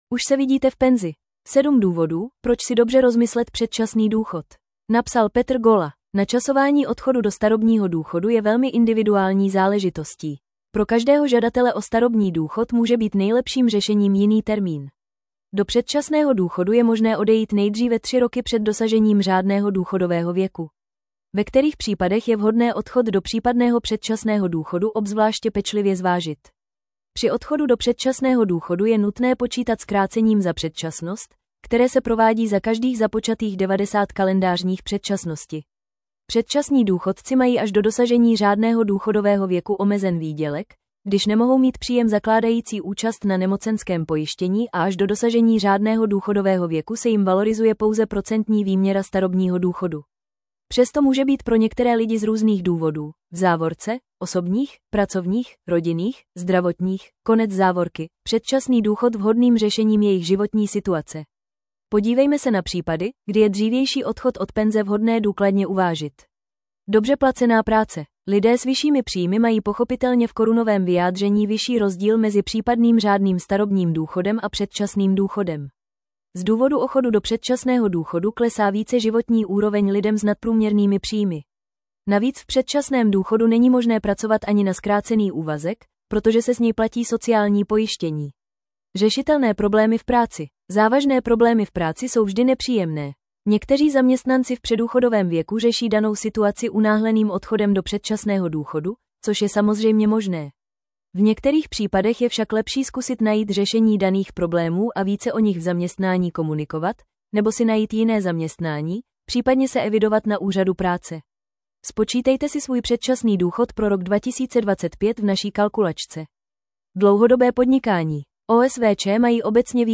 Tento článek pro vás načetl robotický hlas. Jestliže v něm najdete chybu ve výslovnosti, dejte nám prosím vědět.